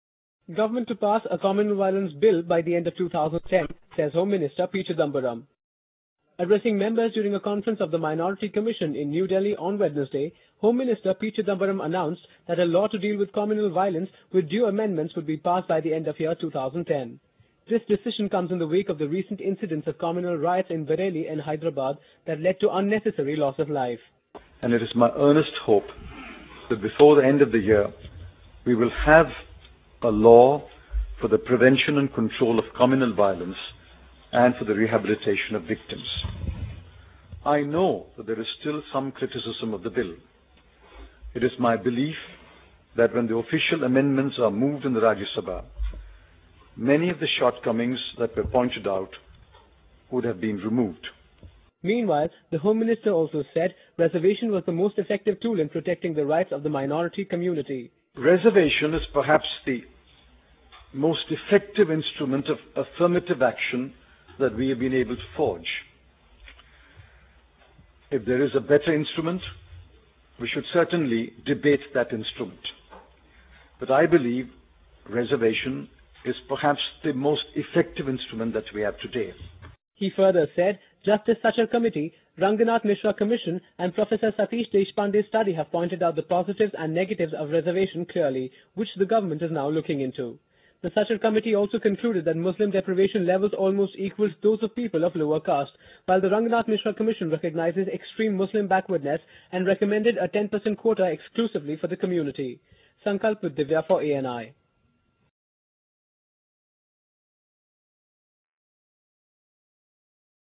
Addressing members during a conference on the Minority Commission in New Delhi on Wednesday Home Minister P Chidambaram announced that a law to deal with communal violence, with due amendments, would be passed by the end of year 2010.This decision comes in the wake of the recent incidents of communal riots in Bareilly and Hyderabad that led to unnecessary loss of life.